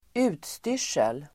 Uttal: [²'u:tstyr_s:el]